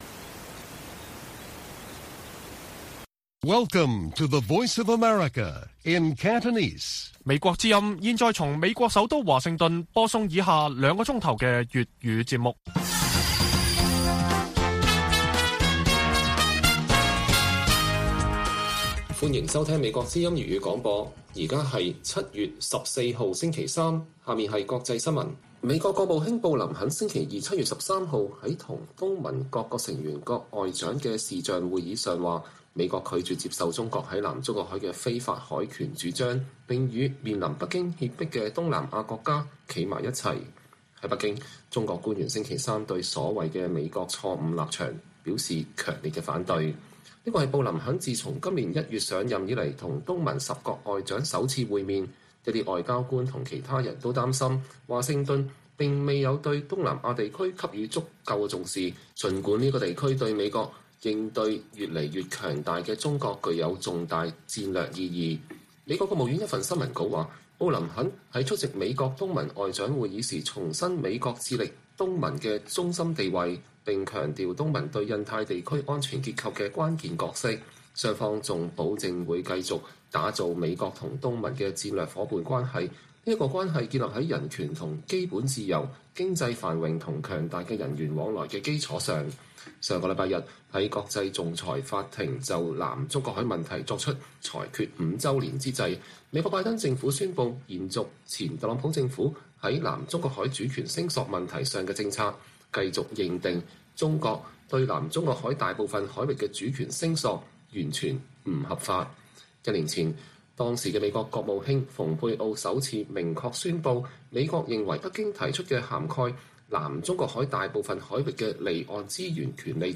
粵語新聞 晚上9-10點: 美國不接受中國在南中國海的非法主權聲索